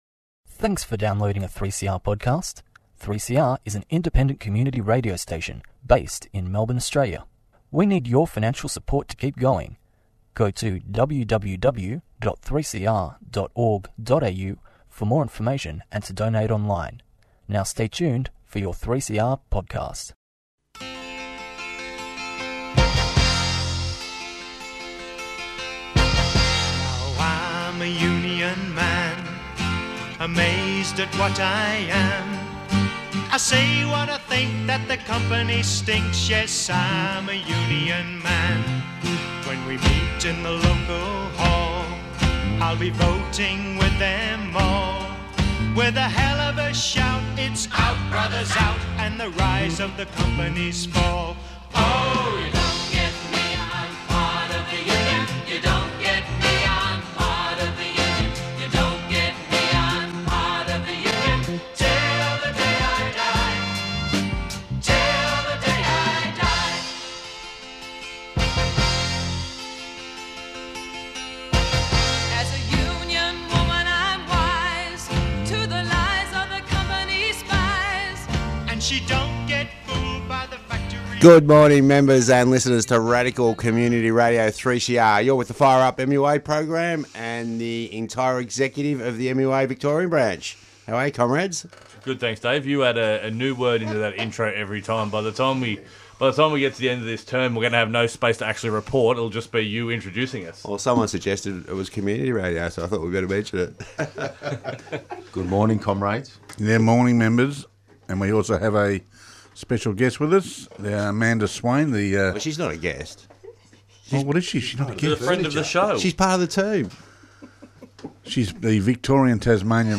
Presented by 3CR affiliate the Maritime Union of Australia.